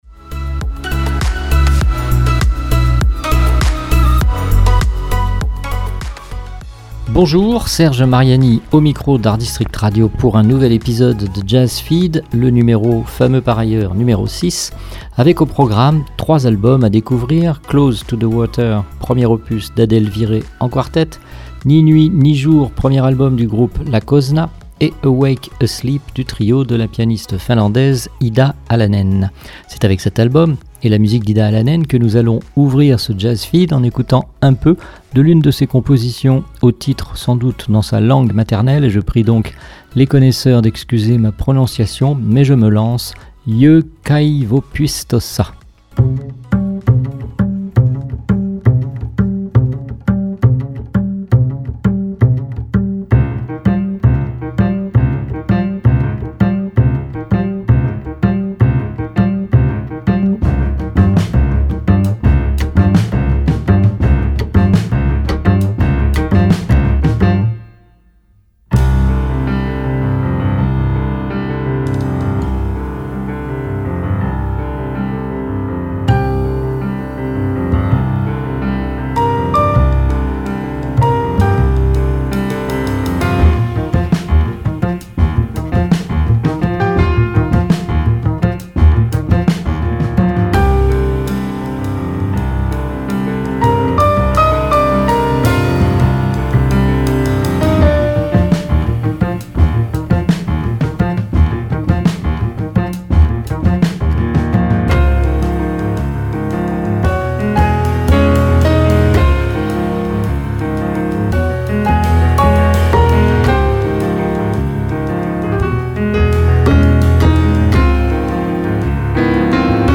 un choix d’albums sortis récemment ou sur le point de l’être, des extraits de leur musique, des informations, des commentaires, des impressions, des émotions